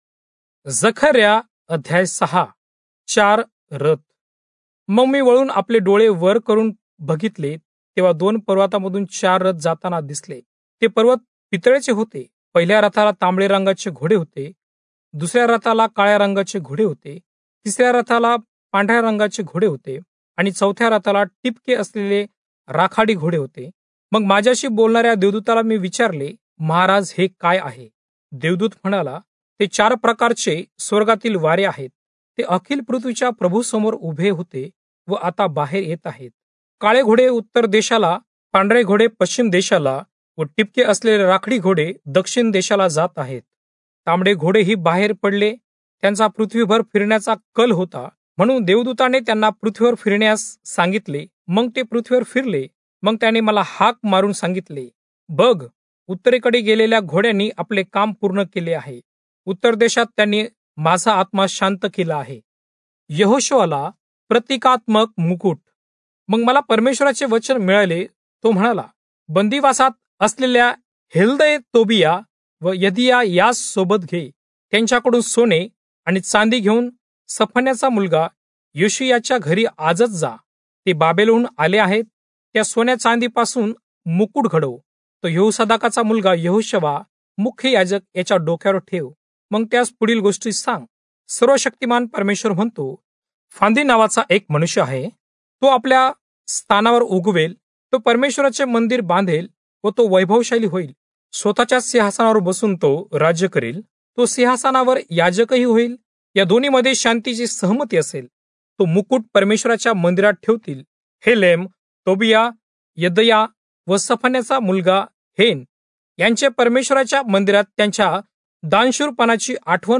Marathi Audio Bible - Zechariah 12 in Irvmr bible version